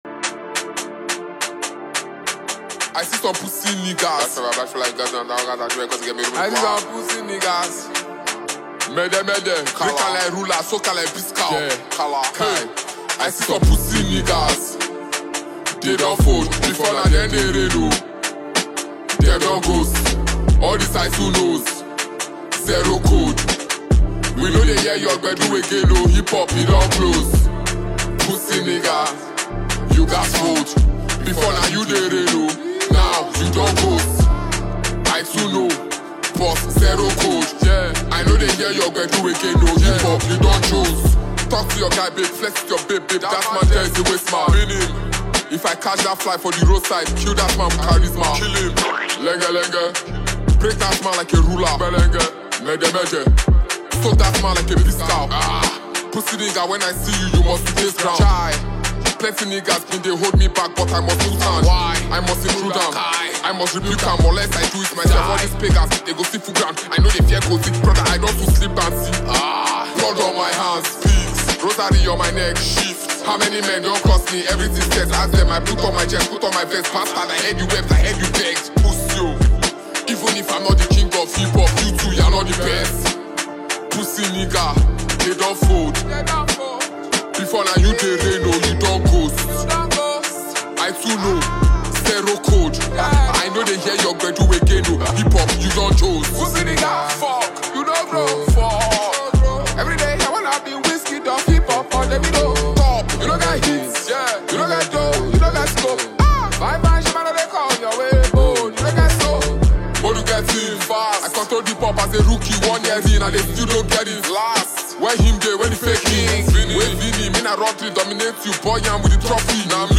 Phenomenon talented Nigerian rap sensation and performer